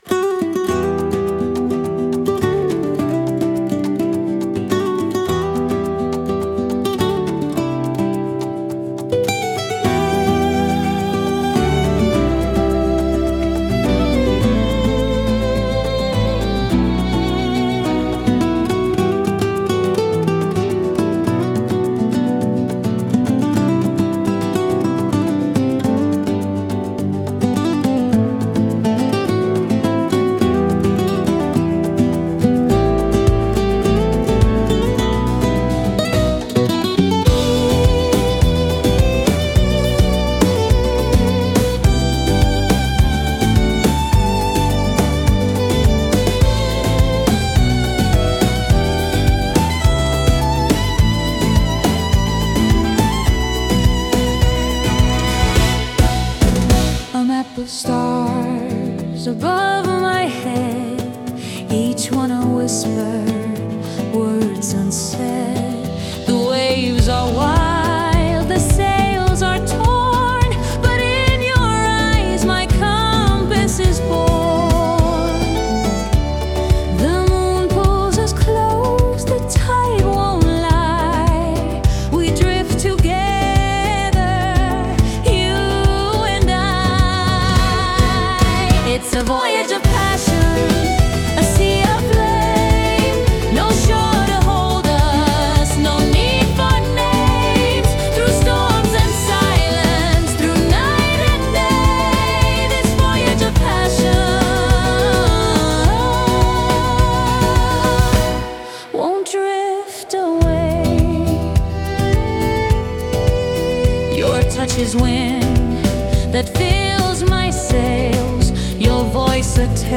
Solo violin and Latin rhythms will free your soul.
旅、発見、そして人生の喜びを描いた、情熱的で明るいインストゥルメンタル。
ソロ・ヴァイオリンとラテンのリズムが、心を解き放ちます。